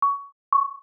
Listen to a 1121 Hz tone followed by the combination tone